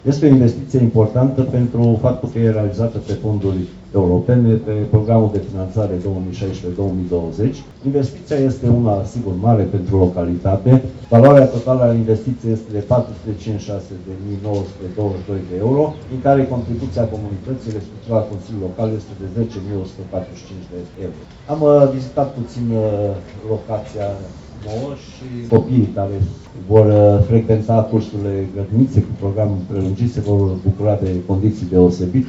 Prefectul județului Mureș, Mircea Dușa, a precizat că este vorba despre o investiție importantă, a cărei valoare depășește 450.000 de euro: